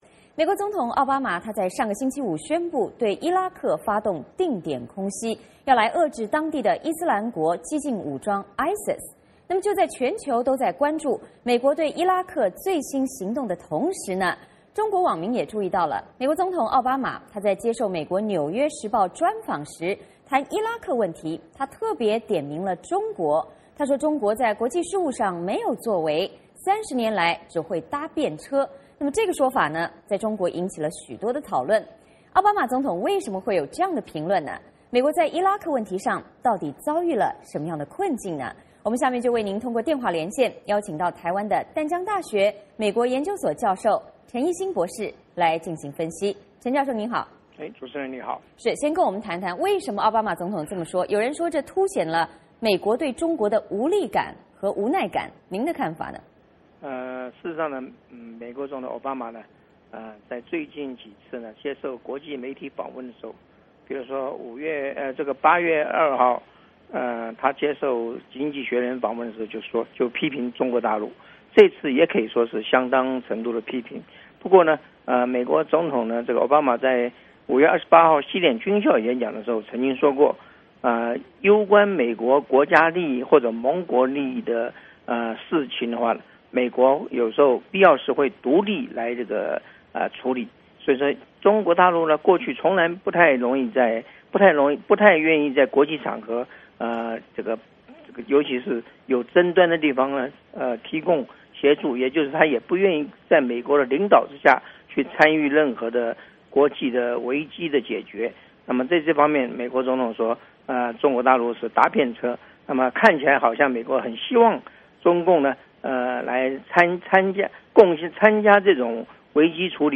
下面我们通过电话连线